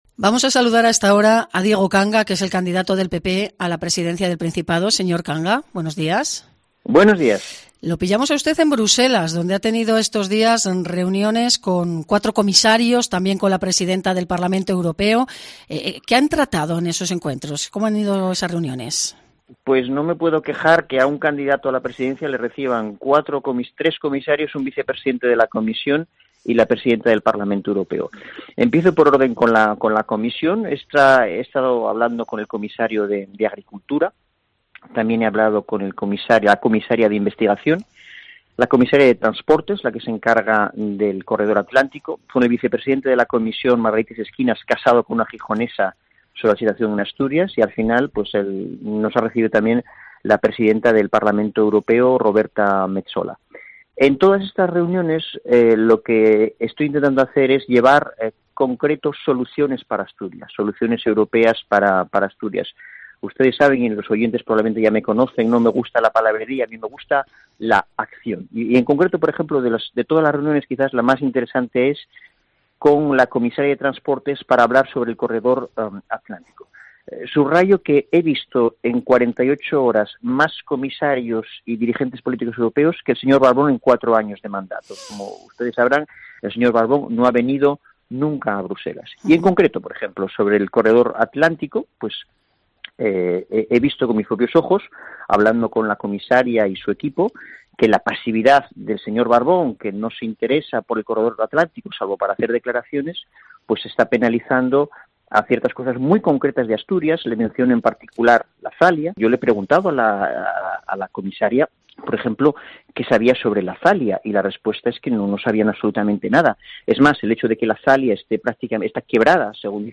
Entrevista a Diego Canga en COPE Asturias